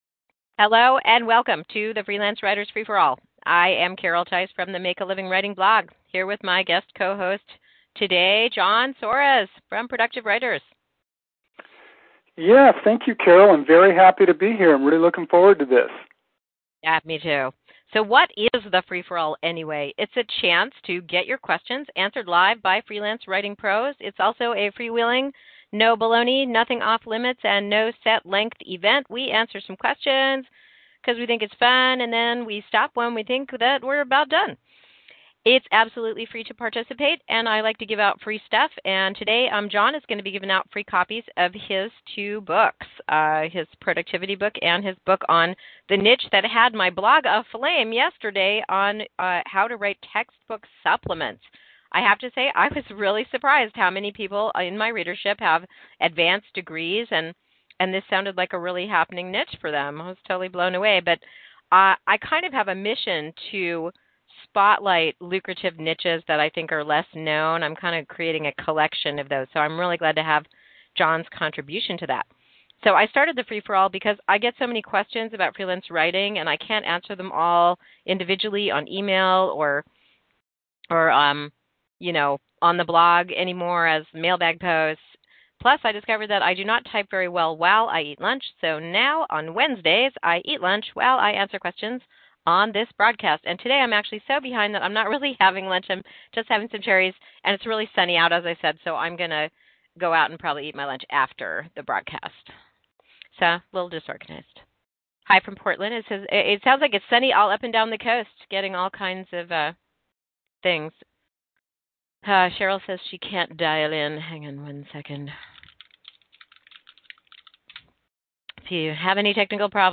The interview is nearly 45 minutes long.